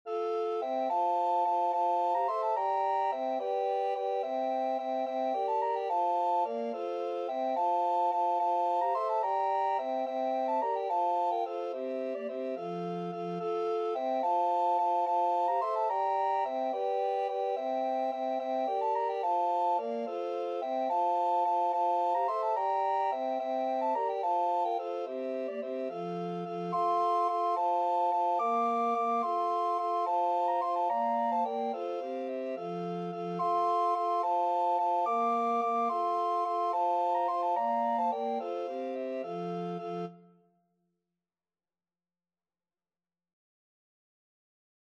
Free Sheet music for Recorder Quartet
Soprano RecorderAlto RecorderTenor RecorderBass Recorder
C major (Sounding Pitch) (View more C major Music for Recorder Quartet )
6/4 (View more 6/4 Music)
Classical (View more Classical Recorder Quartet Music)